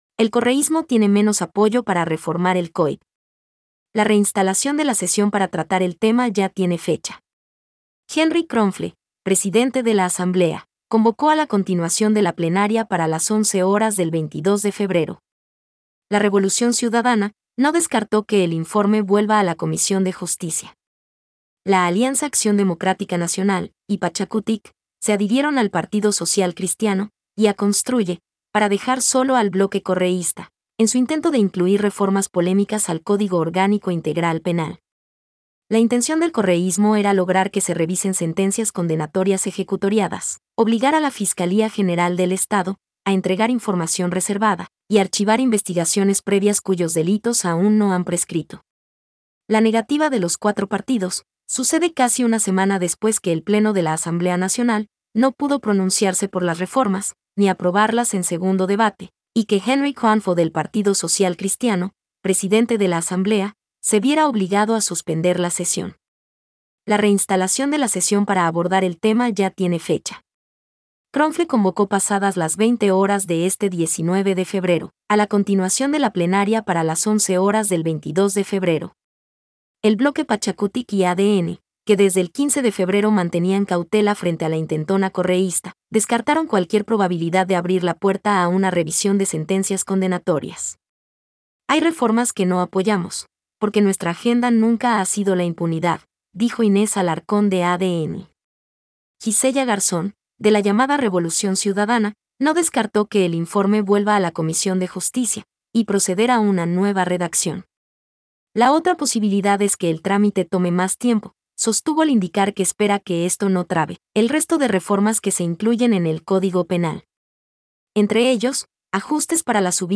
Noticia hablada